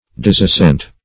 disassent - definition of disassent - synonyms, pronunciation, spelling from Free Dictionary
disassent.mp3